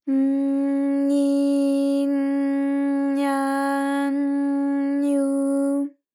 ALYS-DB-001-JPN - First Japanese UTAU vocal library of ALYS.
ny_J_nyi_J_nya_J_nyu.wav